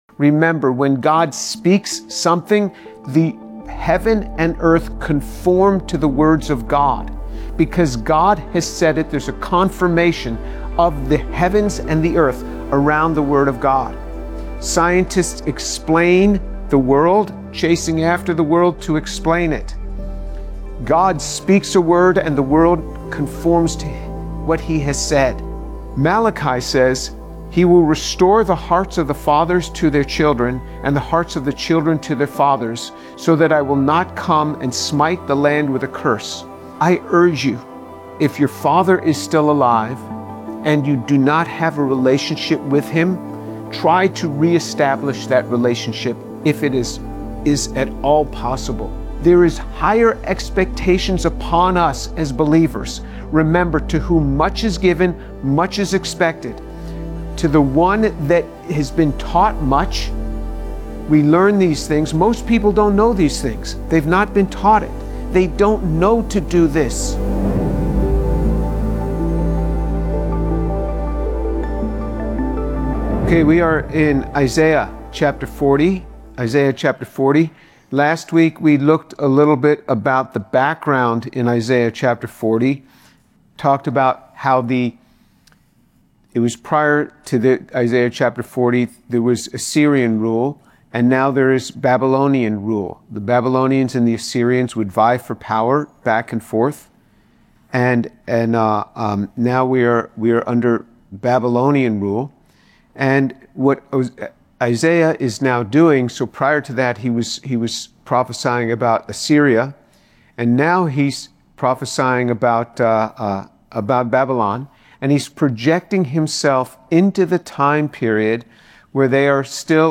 In this sermon on Isaiah 40, Dr. James Tour highlights God’s shift from judgment to comfort for Israel after Babylonian exile. He explains the prophetic transition to messages of restoration, the symbolism of “double” punishment and blessing for God’s chosen people, and how John the Baptist fulfilled the prophecy of a voice preparing the way for the Messiah, while also reflecting on restoring relationships, especially between fathers and children, as part of God’s plan for renewal.